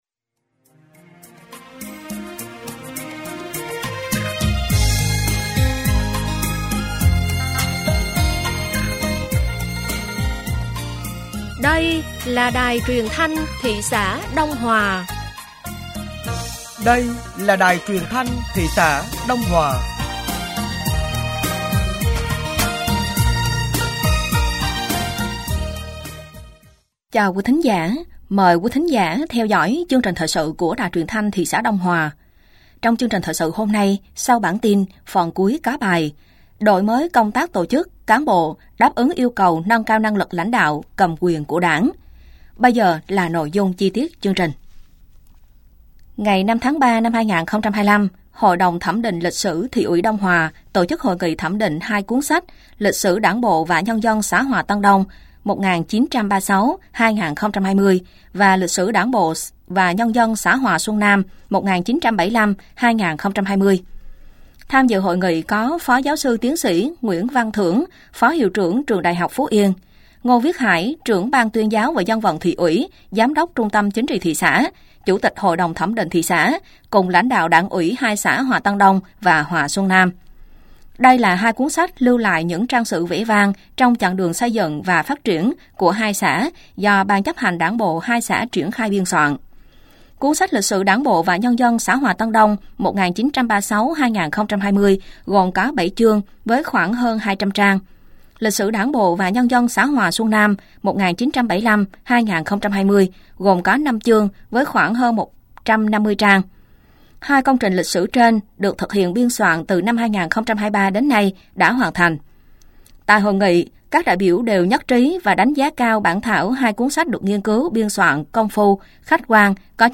Thời sự tối ngày 05 và sáng ngày 06 tháng 3 năm 2025